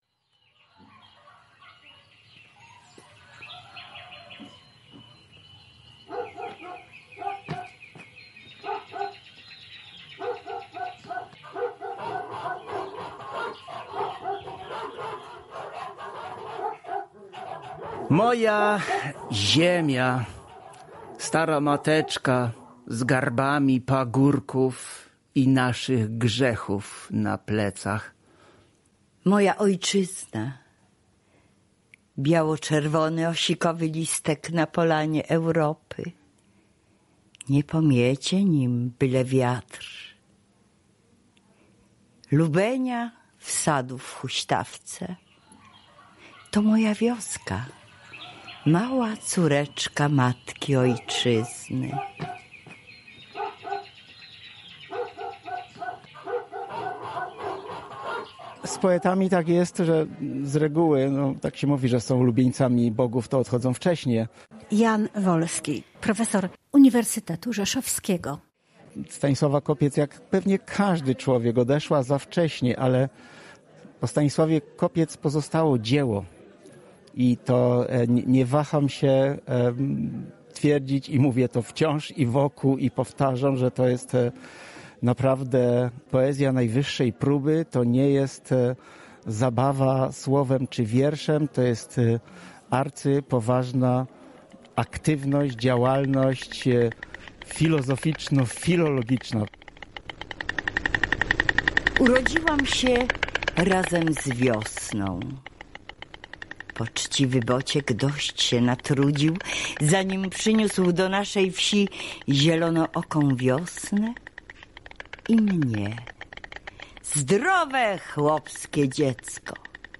Reportaż jest relacją tego wydarzenia.